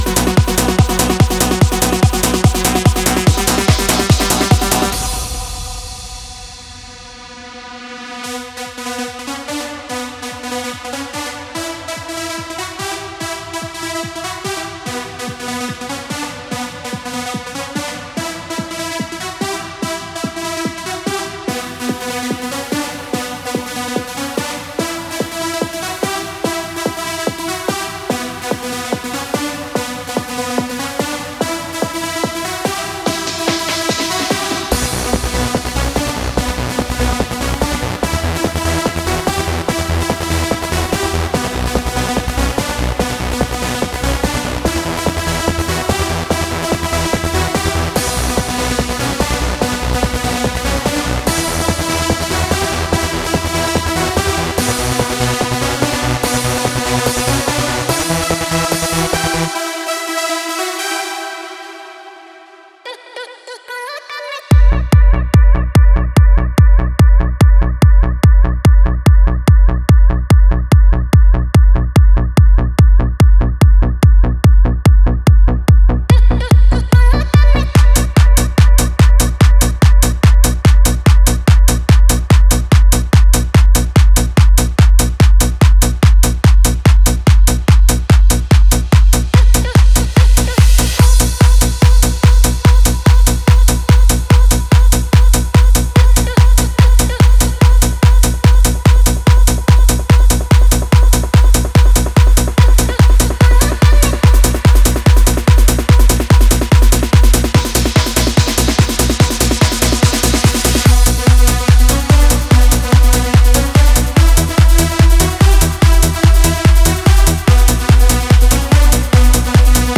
Styl: Techno, Trance